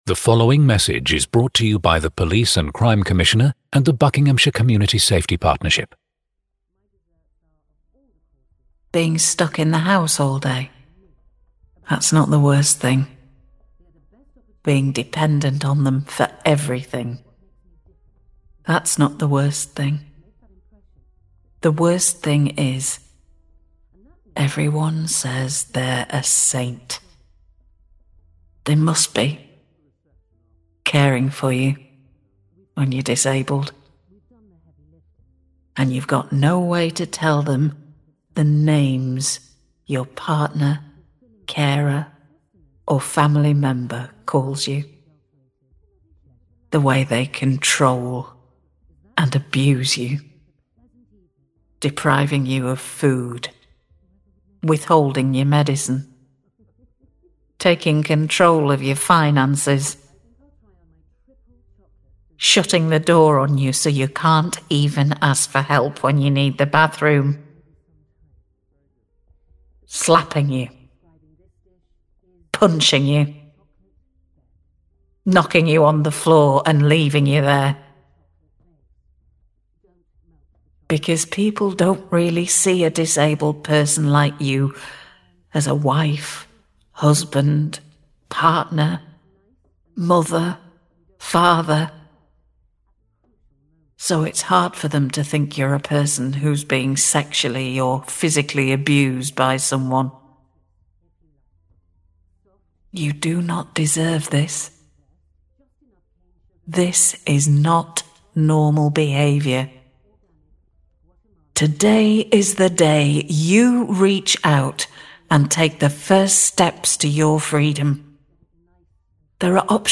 This is a spot (or maybe a PSA) for the Buckinghamshire County Council in England dealing with domestic abuse. It’s over 2 ½ minutes long, but wow, what a job on the copy and VO performance.